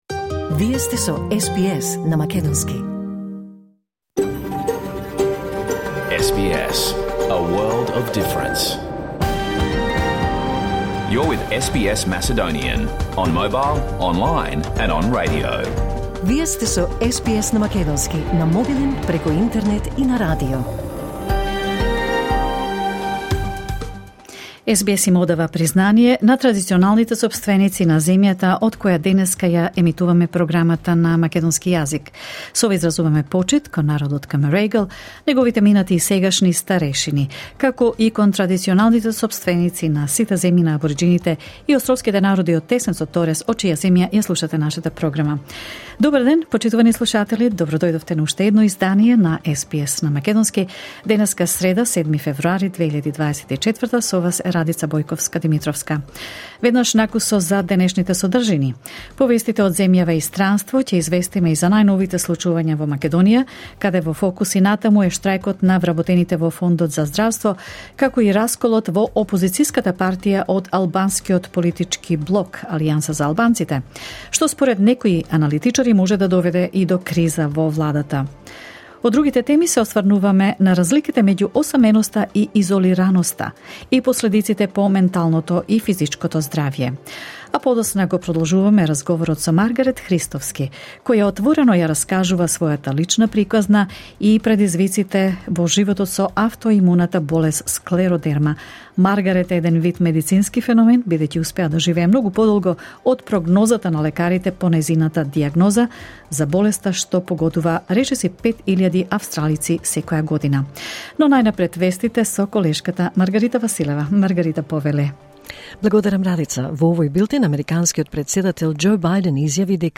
SBS Macedonian Program Live on Air 7 February 2024